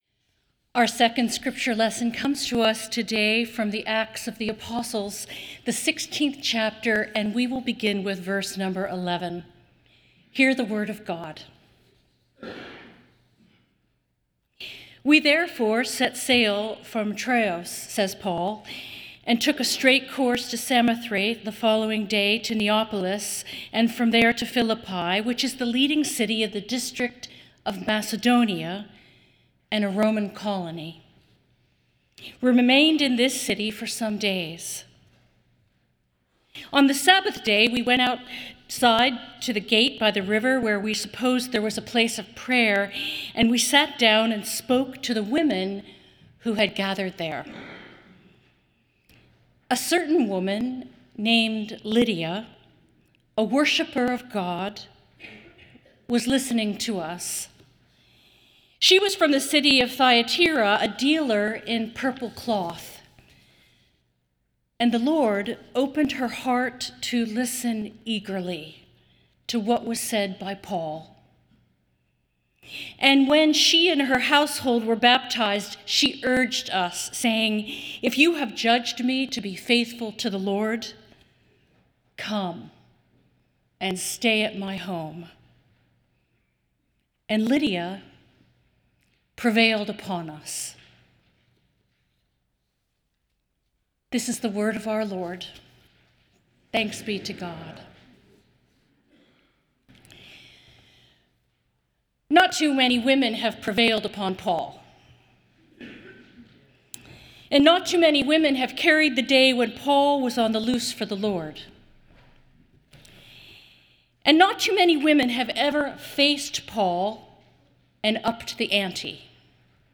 Nassau Presbyterian Church Sermon Journal O Lydia!